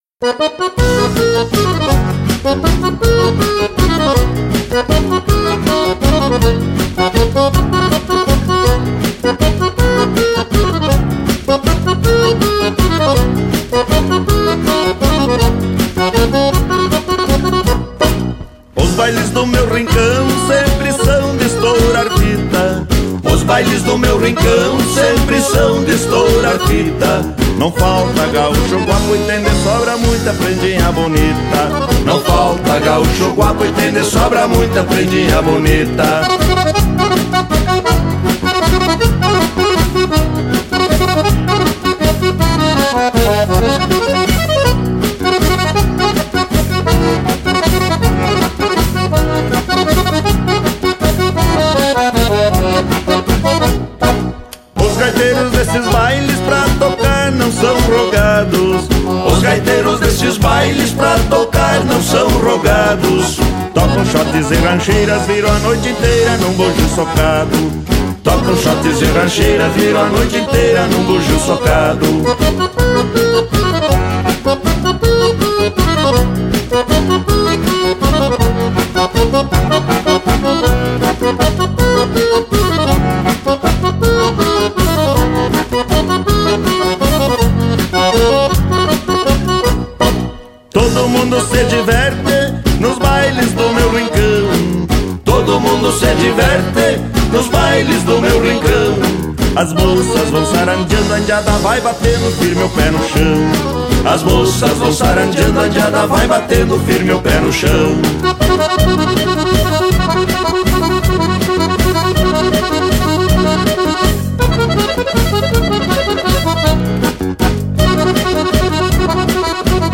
EstiloRegional